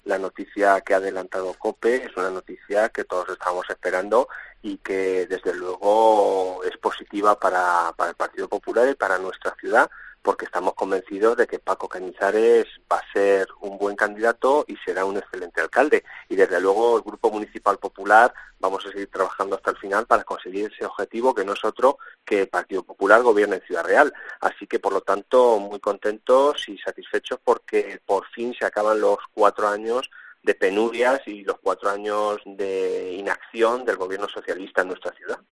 Miguel Ángel Rodríguez, portavoz del Grupo Popular en el Ayuntamiento de Ciudad Real, sobre Francisco Cañizare